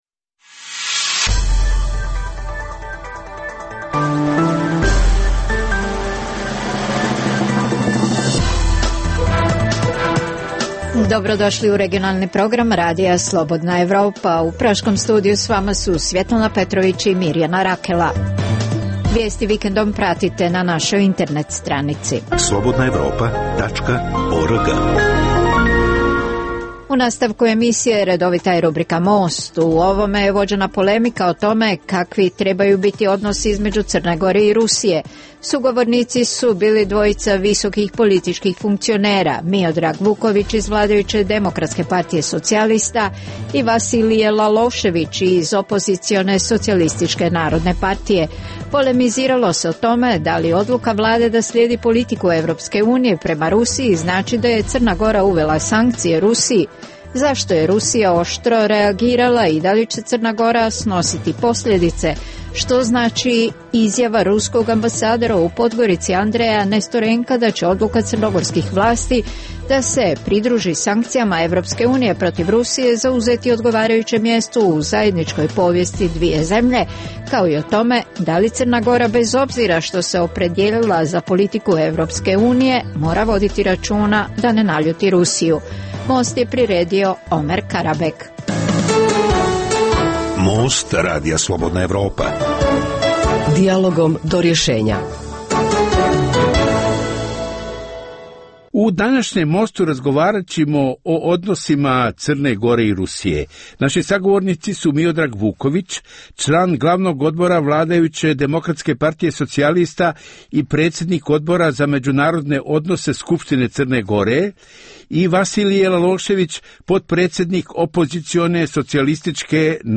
Emisija o dešavanjima u regionu (BiH, Srbija, Kosovo, Crna Gora, Hrvatska) i svijetu.
Ovaj put tema je odnosi Crne Gore i Rusije. Sagovornici su Miodrag Vuković iz vladajuće Demokratske partije socijalista i Vasilije Lalošević iz opozicione Socijalističke narodne partije.